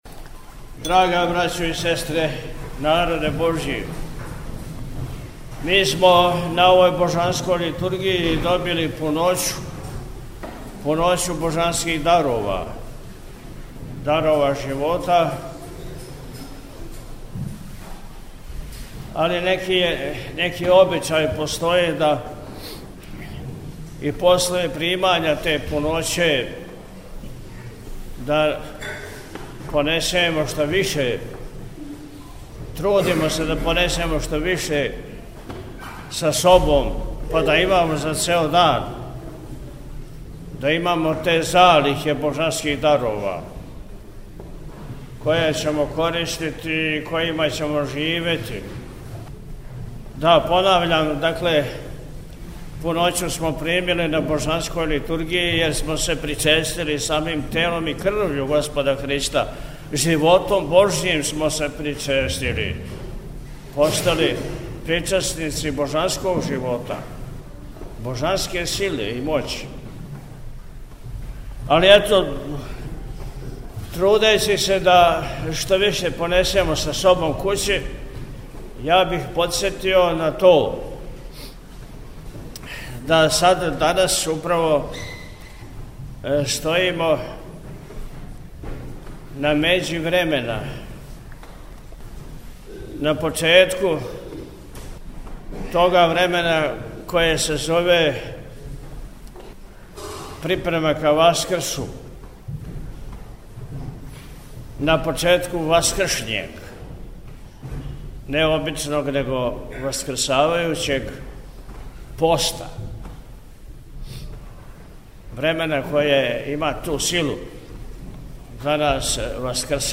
Након отпуста Високопреосвећени је поучио сабрани верни народ подсетивши на значај и значење Великог Васкршњег поста, који нам предстоји: – Данас стојимо на међи времена, на почетку тога времена које се зове припрема ка Васкрсу, на почетку Васкршњег, не обичног него васкрсавајућег, поста, времена које има ту силу да нас васкрсава.